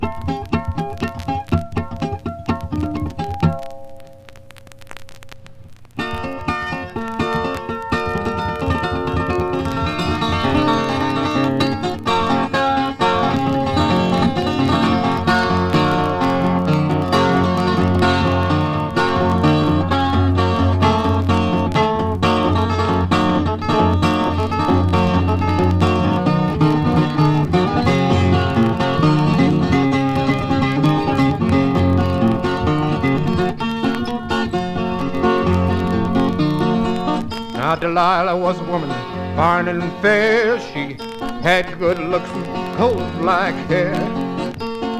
Blues, Folk, World　USA　12inchレコード　33rpm　Mono